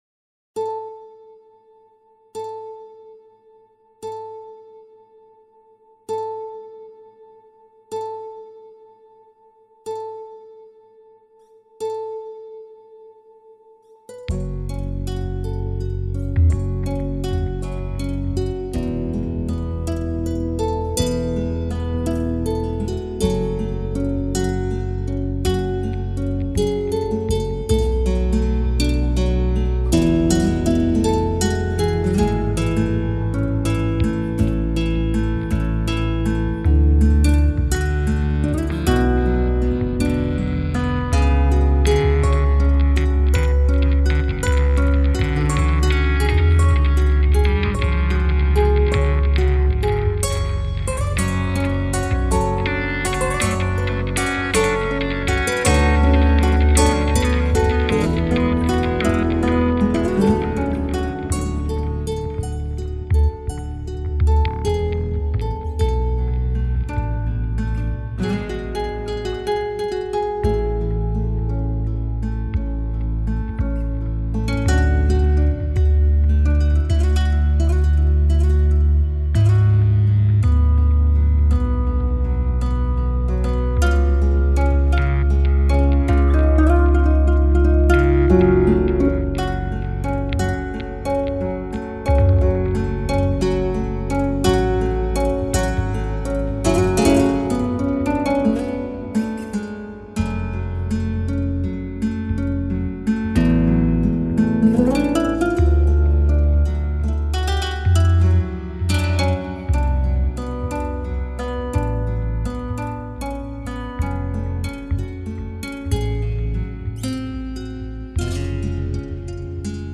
sam pro x3 oder x2 .... 160bpm etwa 3/4 midi vita2 a-Gitarre 303 als Begleitung . DAW A-Ton 432HZ HaLLO! Es ist ein Songversuch , wo ich die Midinoten+controller weitesgehend schon nachgetunt habe, per Hand .Hier und da fluscht es noch nicht so und ich muß an der Interpretation der Parts noch drehen .
In dem "Trackversuch" 2Spur Songdemo ist alles nur Probe , ich habe mich noch für nichts entschieden , was Effekte etc anbelangt .